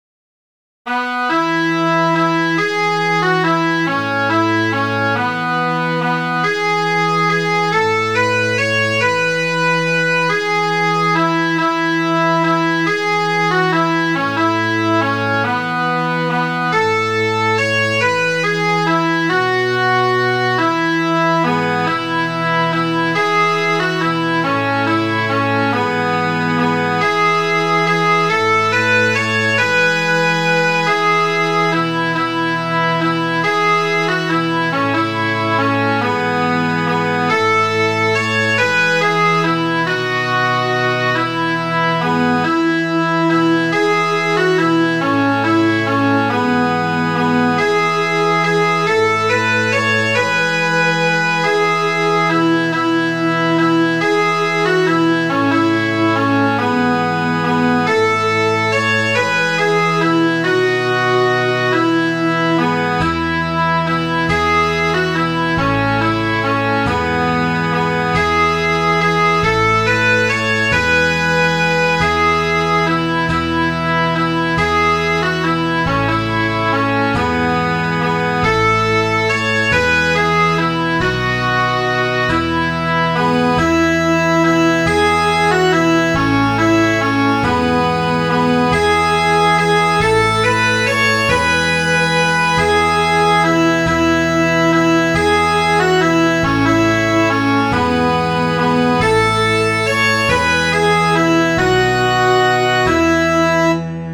Midi File, Lyrics and Information to Rare Willie